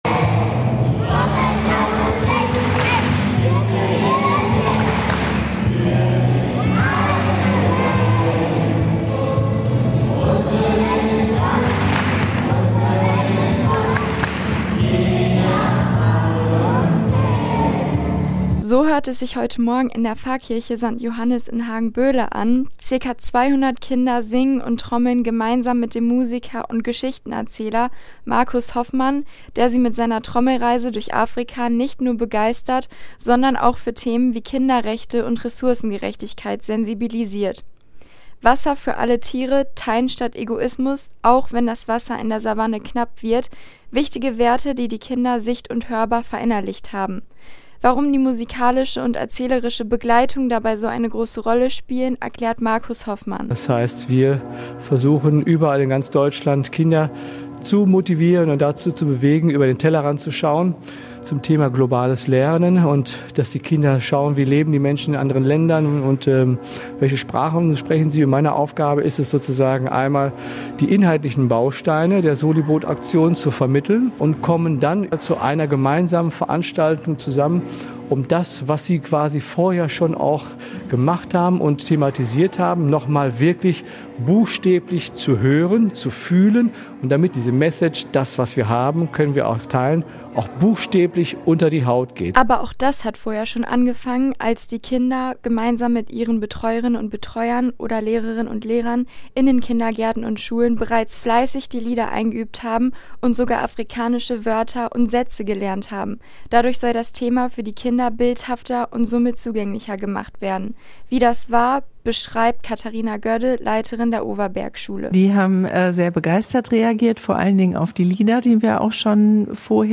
Kinder der katholischen Kindergärten und Schulen im Hagener Norden haben heute eine Trommelreise durch Afrika in der Pfarrkirche St. Johannes in Hagen Boele gemacht.